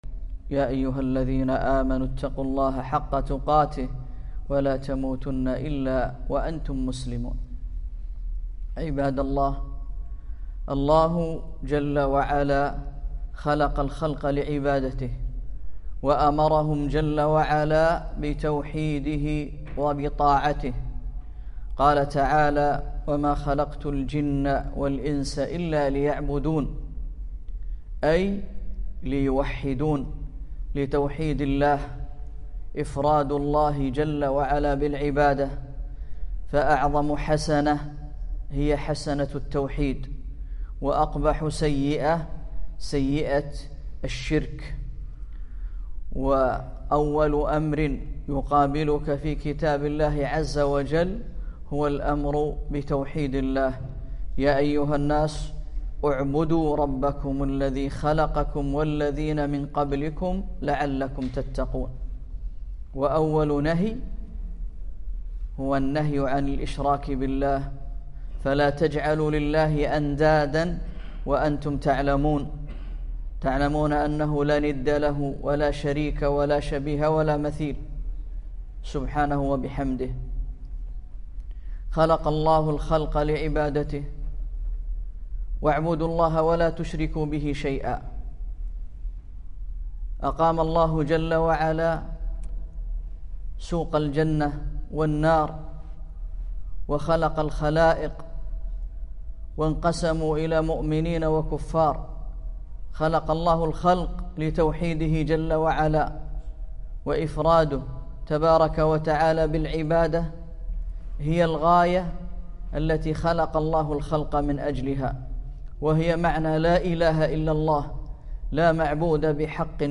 محاضرة - التوحيد وأثره في تحصينك